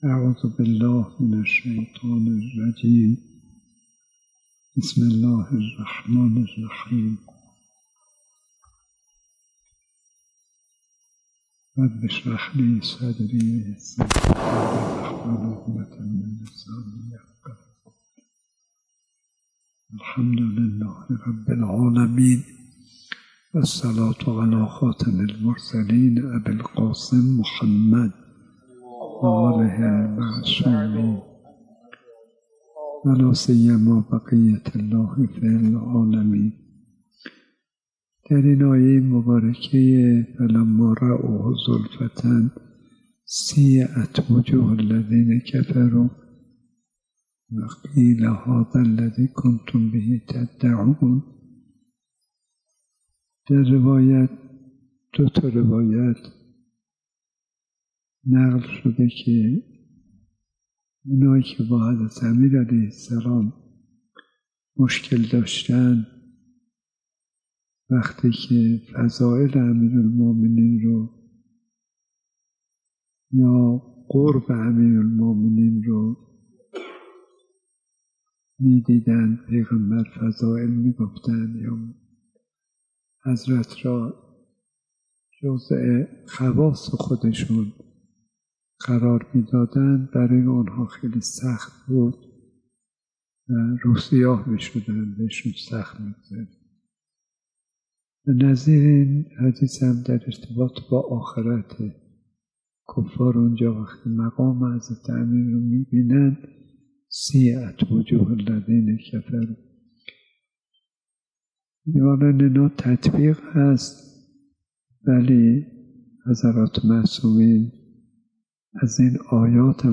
روضه اول ماه ذی الحجه سال ۱۴۴۵ هجری قمری